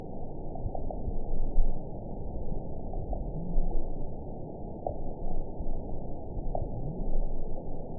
event 921748 date 12/18/24 time 19:26:24 GMT (11 months, 3 weeks ago) score 6.17 location TSS-AB03 detected by nrw target species NRW annotations +NRW Spectrogram: Frequency (kHz) vs. Time (s) audio not available .wav